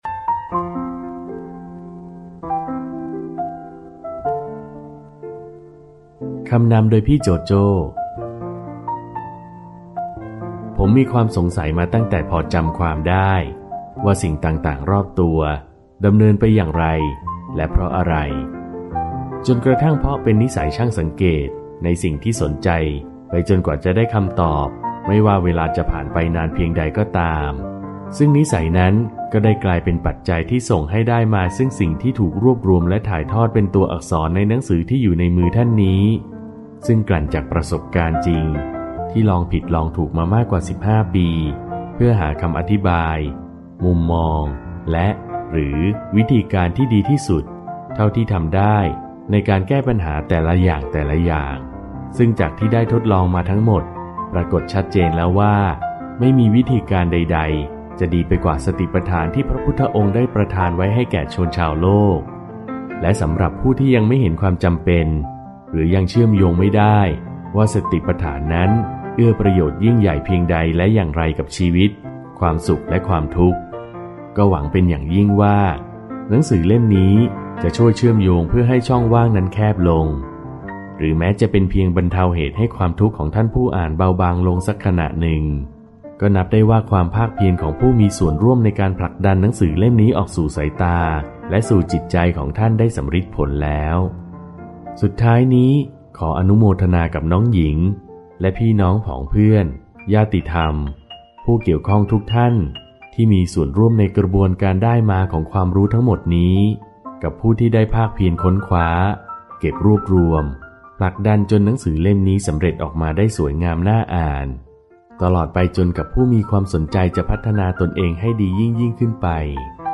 เสียงอ่านหนังสือ "เหตุเกิดจากความรัก"